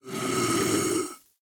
1.21.5 / assets / minecraft / sounds / mob / husk / idle1.ogg